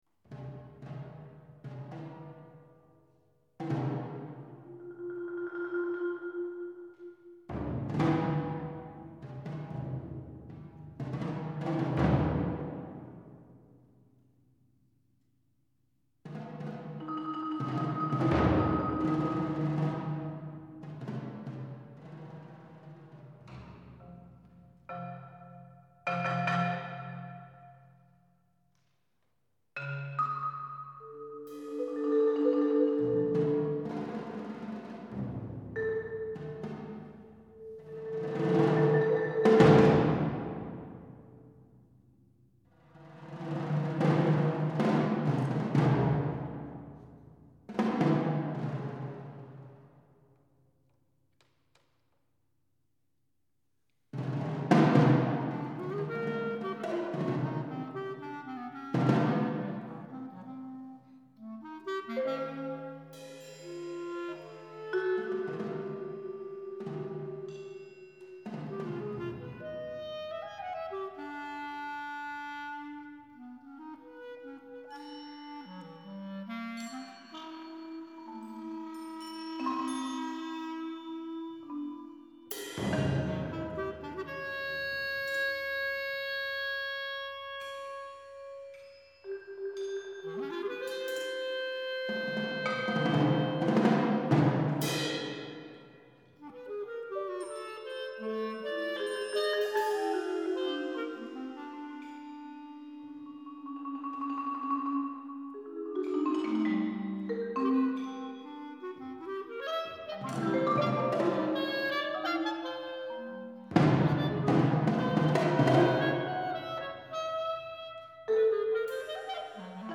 St Paul’s Hall, Huddersfield, England – 6th Februari 2003
clarinet
marimba
percussion